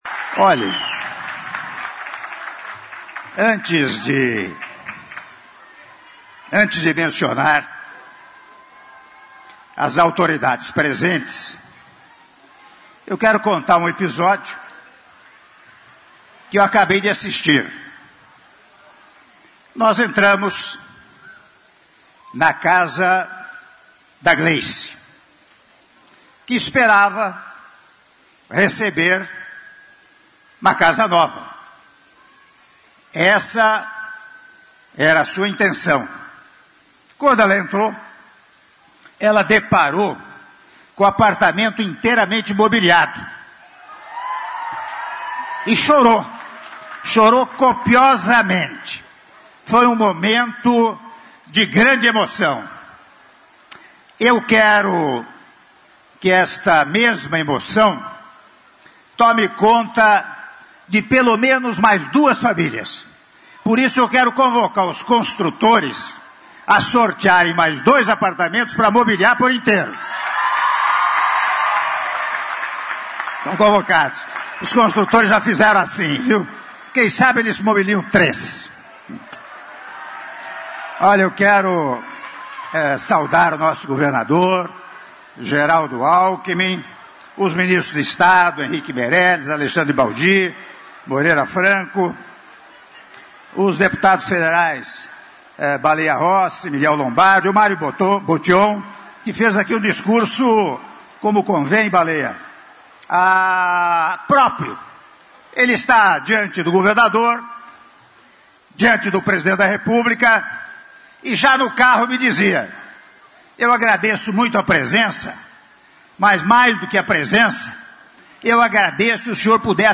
Áudio do discurso do Presidente da República, Michel Temer, durante Cerimônia de Entrega de 900 UH do Condomínio Residencial Rubi III a V do Programa Minha Casa Minha Vida - (08min20s) - Limeira/SP